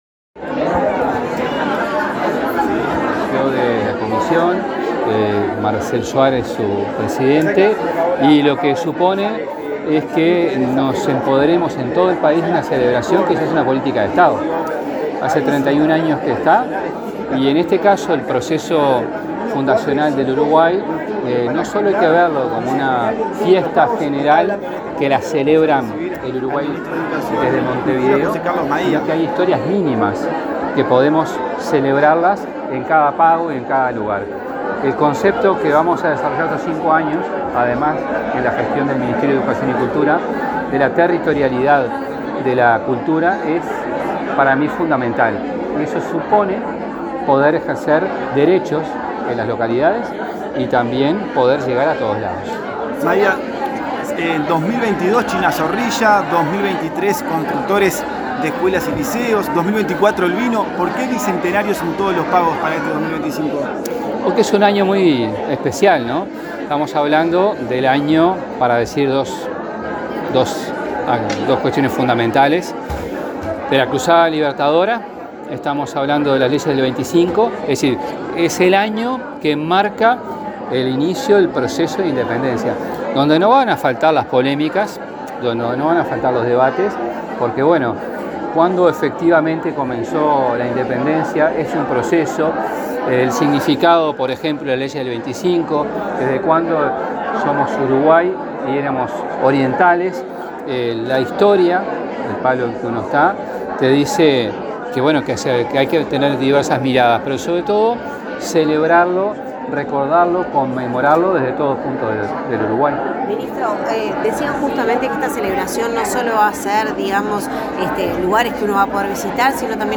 Declaraciones del ministro de Educación y Cultura, José Carlos Mahía
El ministro de Educación y Cultura, José Carlos Mahía, dialogó con la prensa, luego de participar en el acto de lanzamiento del Día del Patrimonio